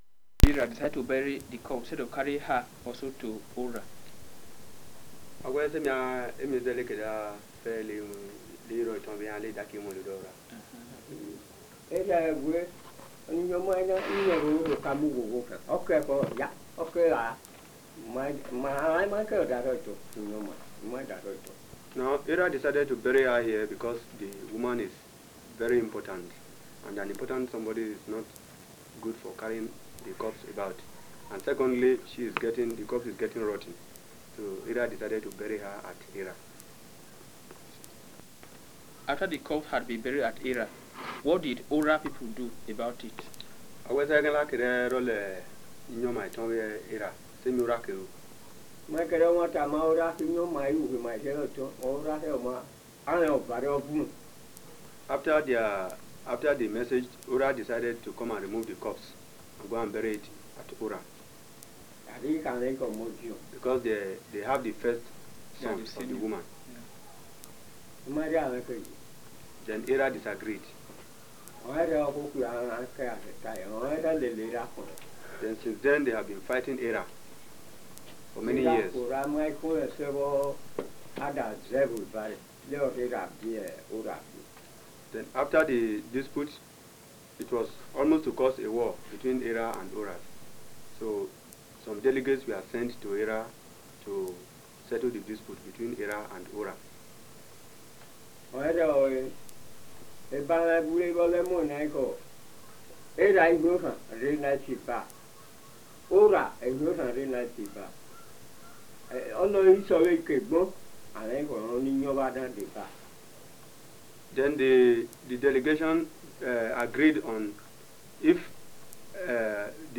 Interview
Nigeria, 1977